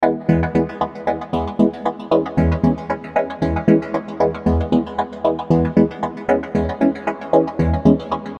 NeonGroove-synth-loop.mp3